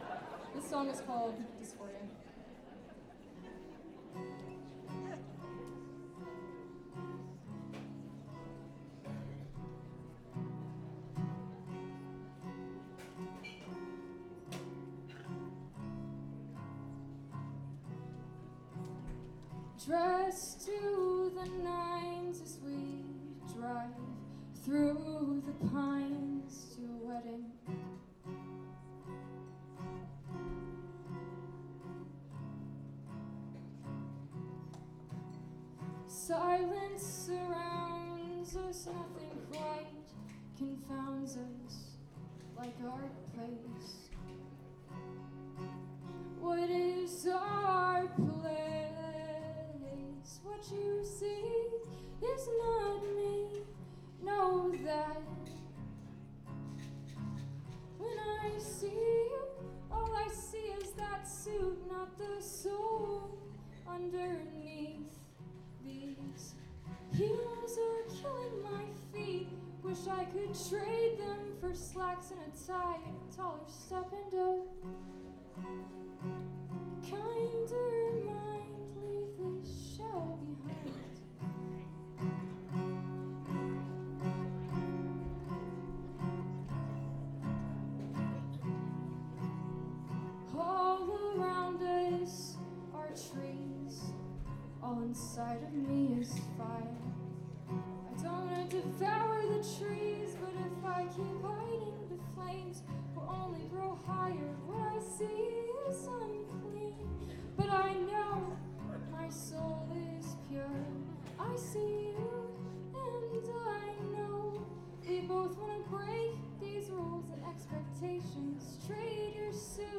lifeblood: bootlegs: 2016-01-03: terminal west - atlanta, georgia (benefit for save the libraries)